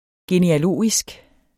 Udtale [ geneaˈloˀisg ]